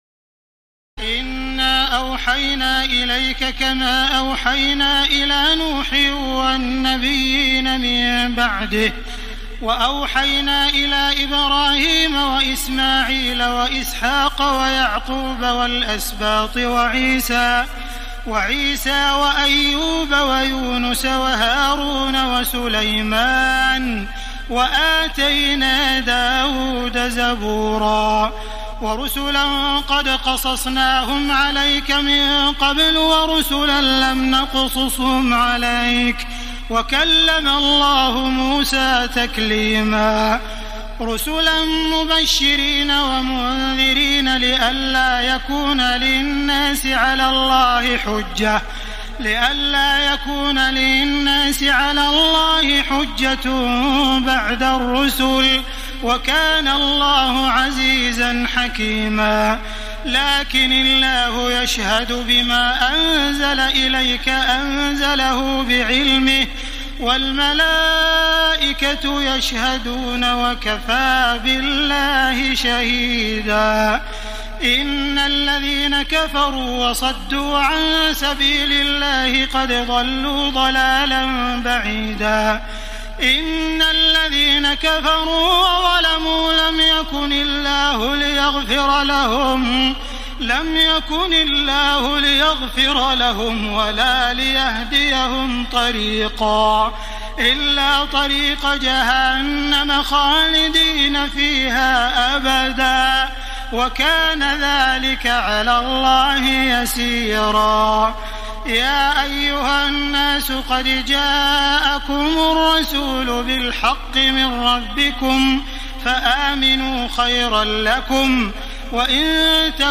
تراويح الليلة الخامسة رمضان 1434هـ من سورتي النساء (163-176) و المائدة (1-40) Taraweeh 5 st night Ramadan 1434H from Surah An-Nisaa and AlMa'idah > تراويح الحرم المكي عام 1434 🕋 > التراويح - تلاوات الحرمين